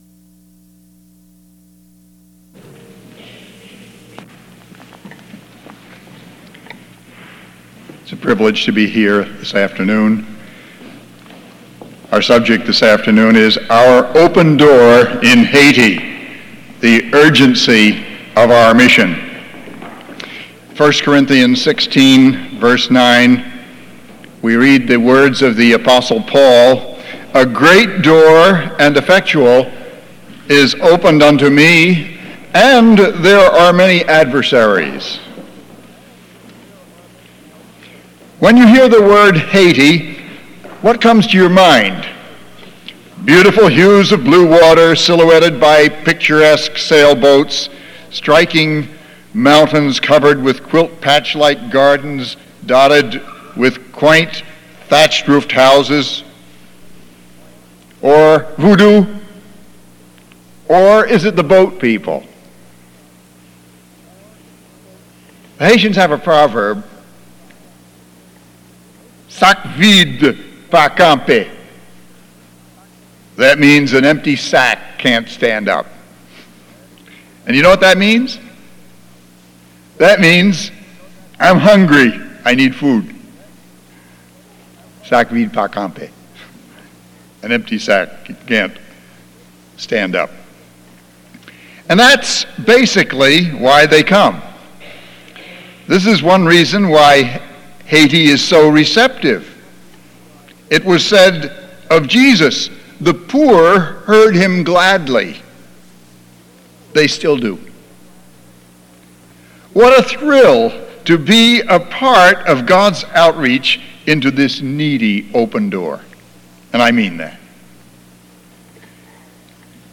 Sunday afternoon missionary service